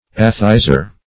atheizer - definition of atheizer - synonyms, pronunciation, spelling from Free Dictionary
-- A"the*i`zer , n. --Cudworth.